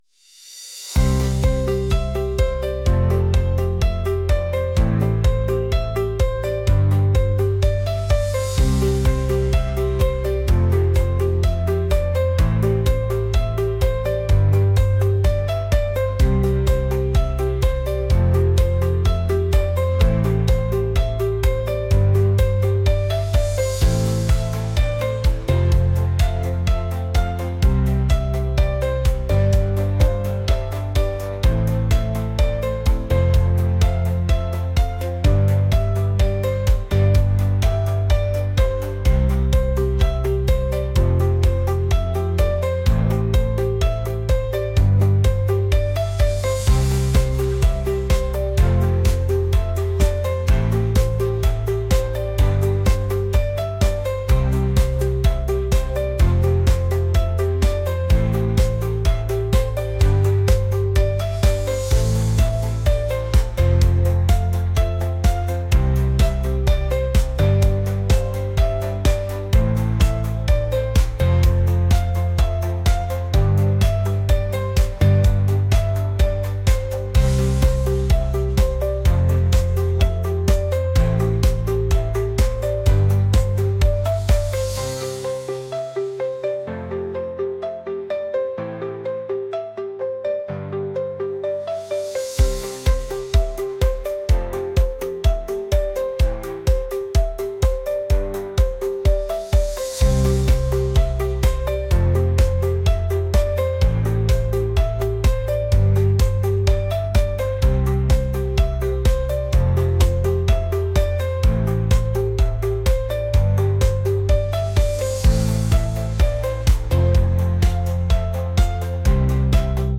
pop | indie | acoustic